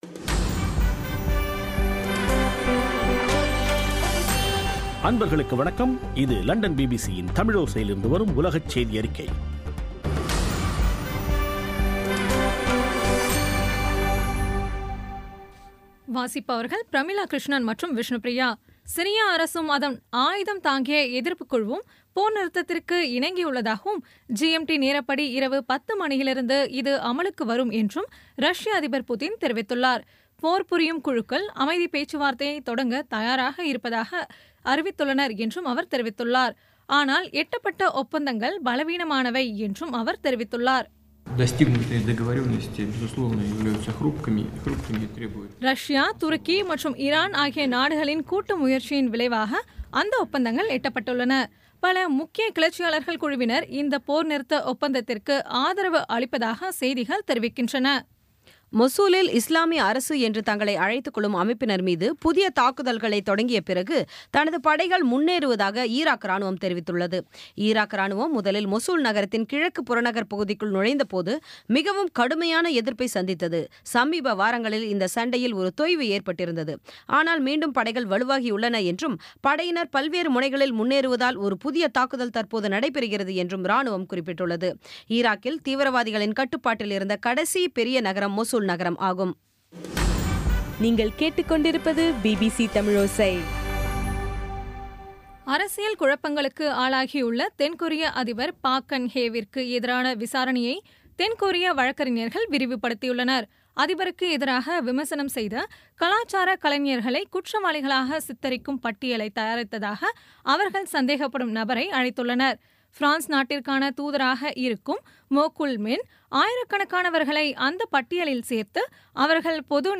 பிபிசி தமிழோசை செய்தியறிக்கை (29/12/2016)